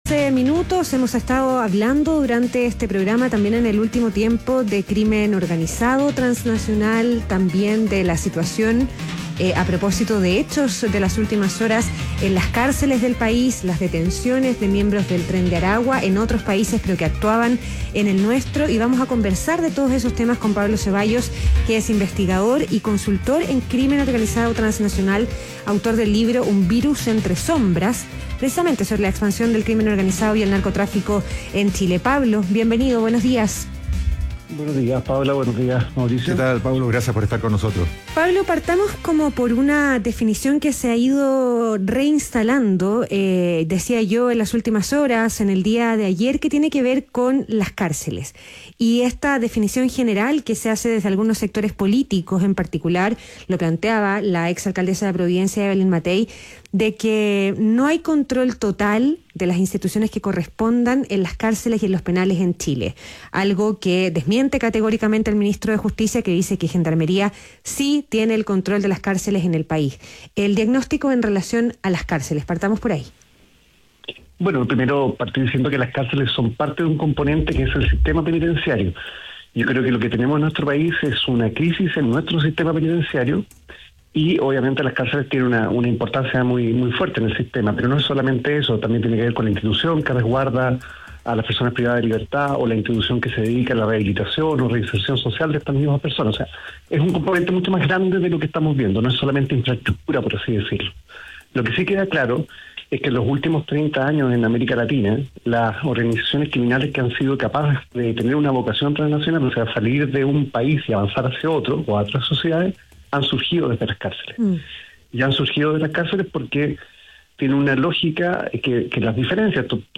ADN Hoy - Entrevista